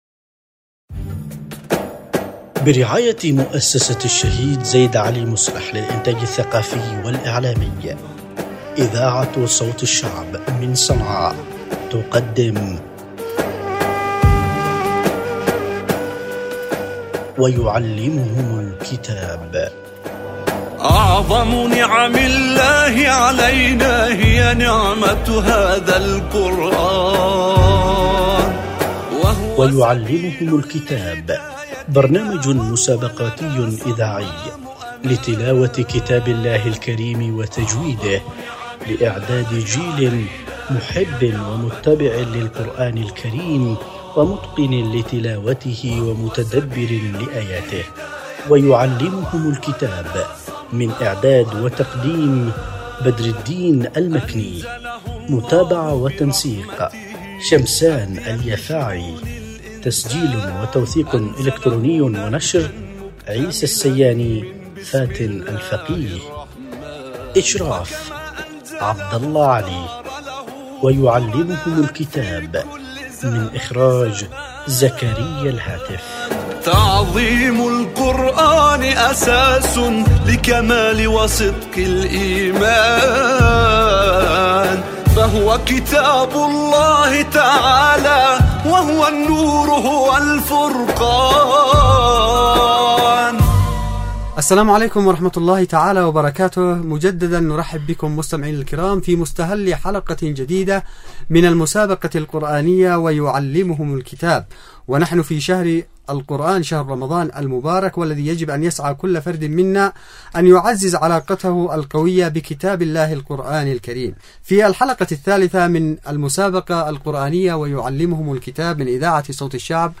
مع المتسابقين